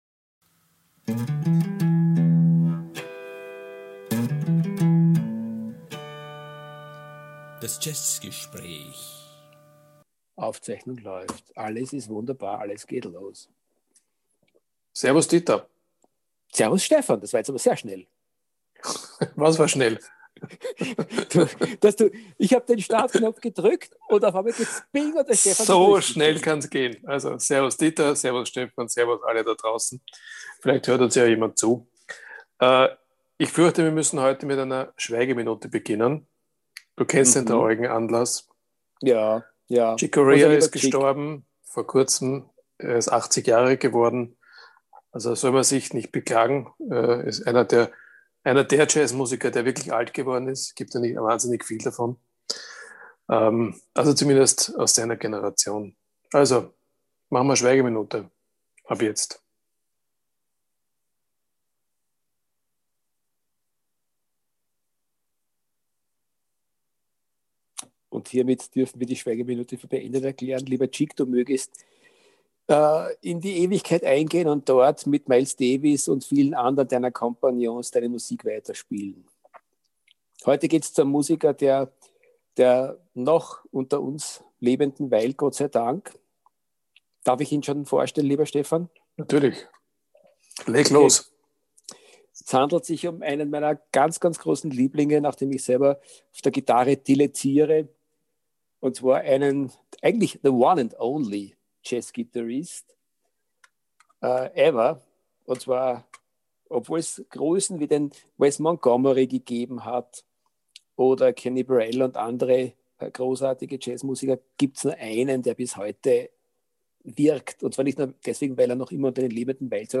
Hört rein in unser Jazzgespräch, Folge 10!